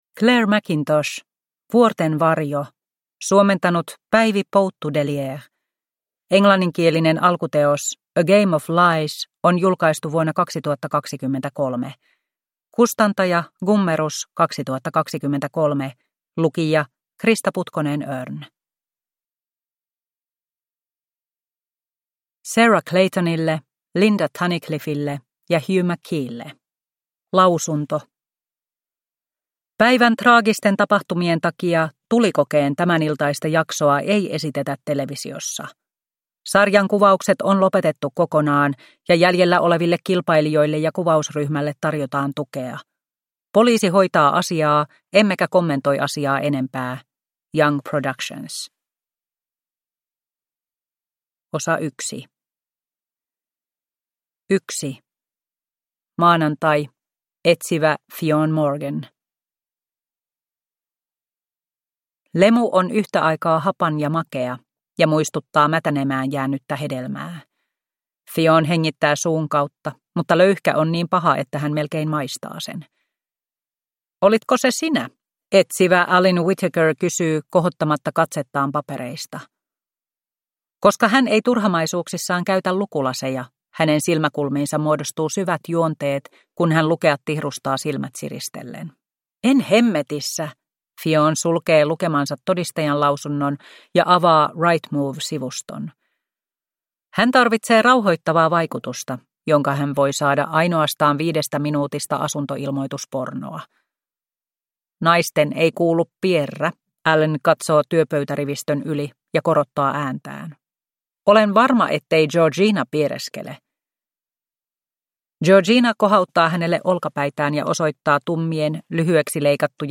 Vuorten varjo – Ljudbok – Laddas ner